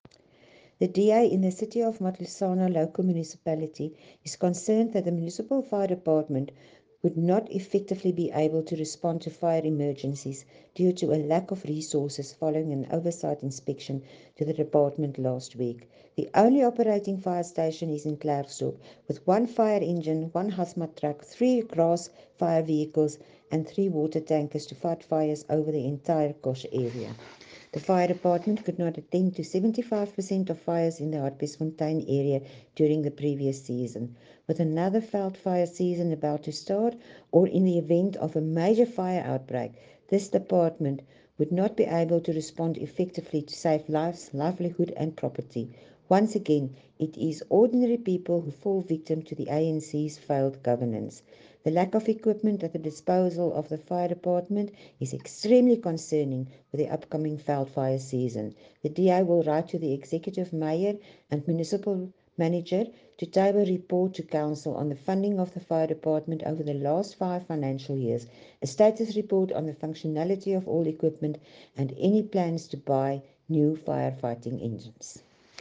Note to Broadcasters: Please find linked soundbites in